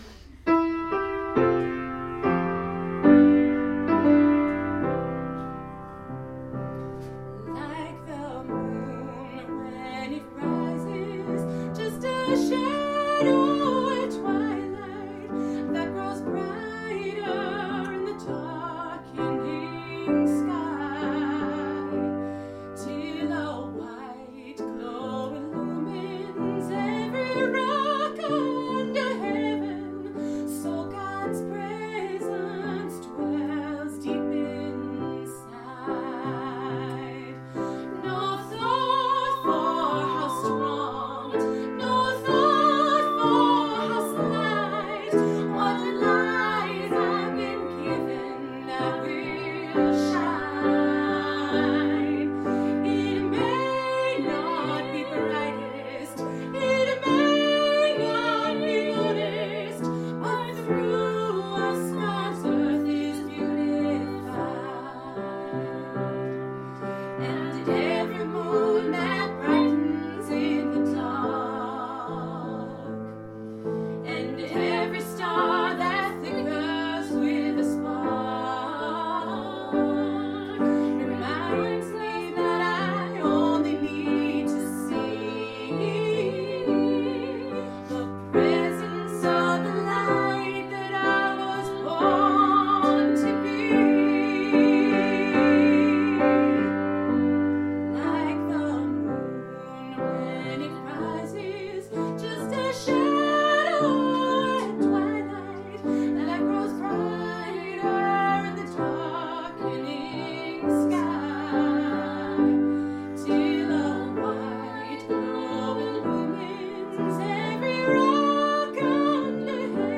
Here are audio recordings from our recital on April 5, 2014 in Marlborough, MA.
mezzo
piano